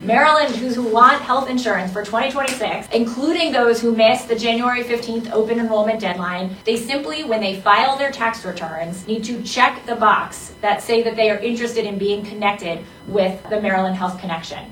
A new system designed to connect those searching for coverage will be part of the Maryland income tax forms this season. State Comptroller Brooke Lierman explains the program is open to all taxpayers…